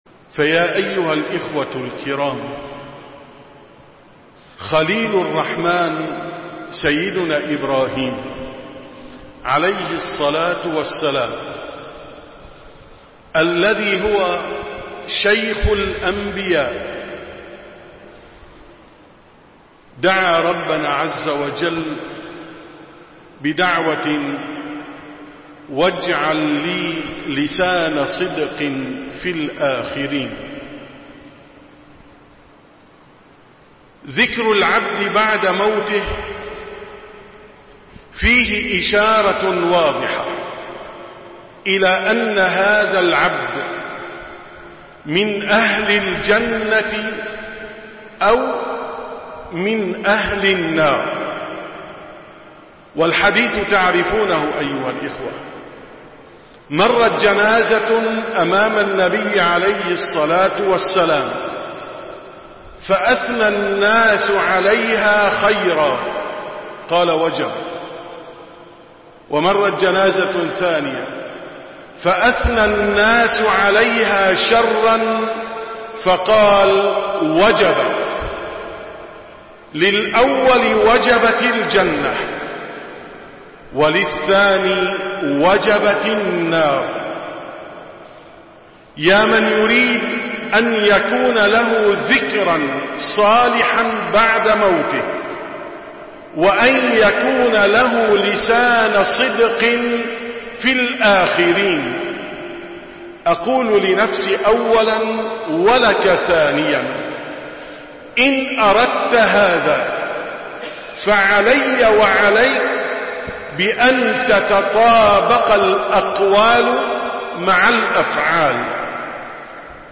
18- كلمة مختصرة في صلاة التراويح 1440هــ: اترك لنفسك ذكراً صالحاً
دروس رمضانية